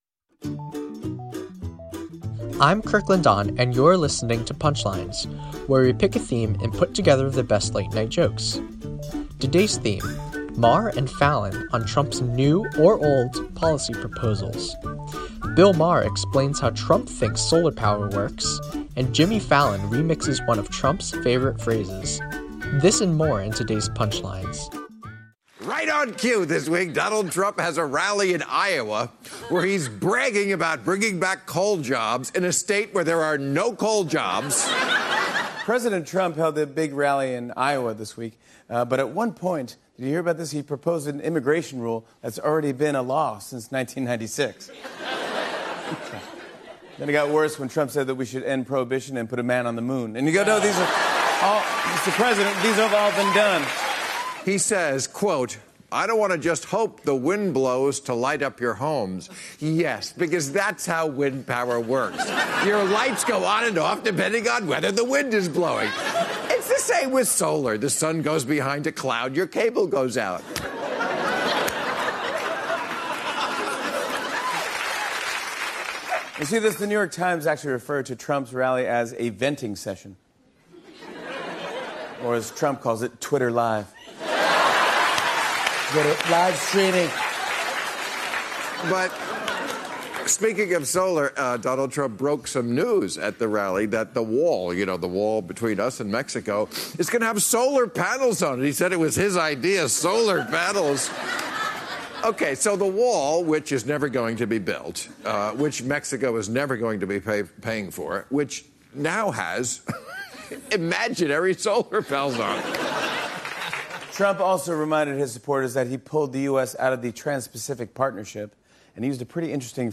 The late-night comics on Trump's rally in Iowa.